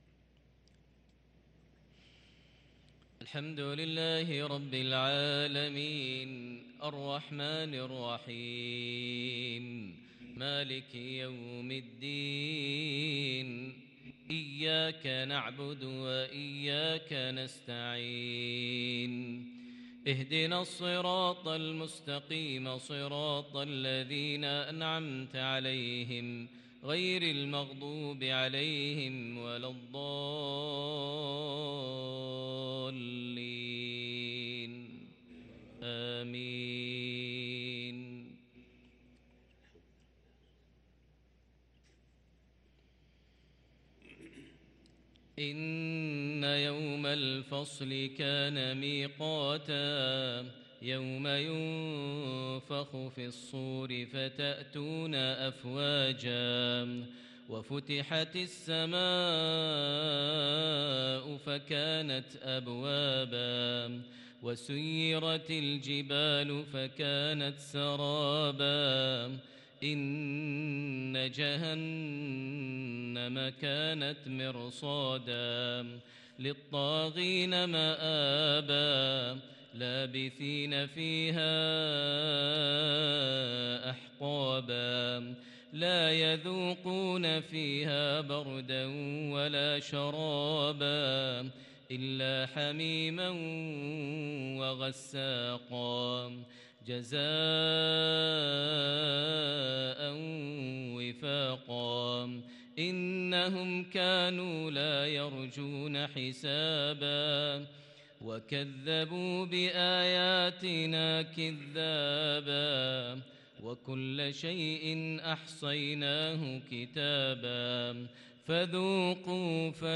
صلاة المغرب للقارئ ماهر المعيقلي 3 ذو القعدة 1443 هـ
تِلَاوَات الْحَرَمَيْن .